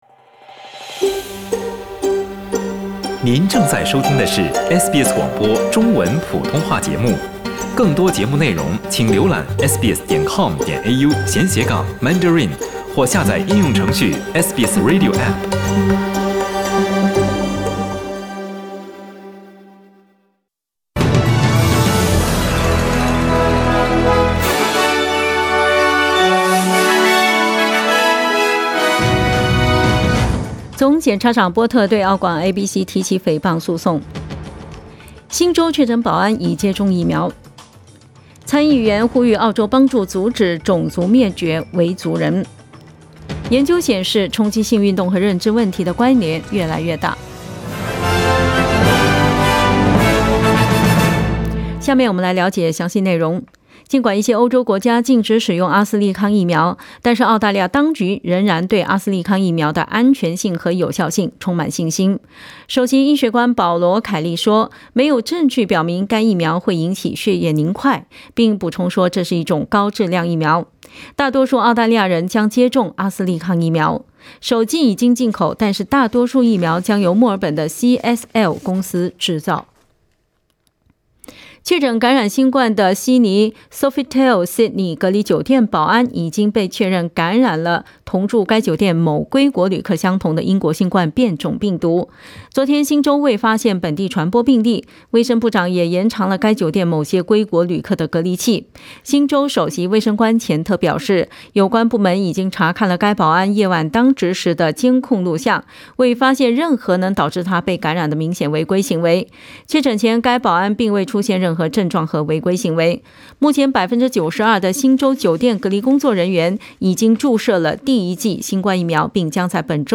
SBS 早新聞 （3月16日）
SBS Mandarin morning news Source: Getty Images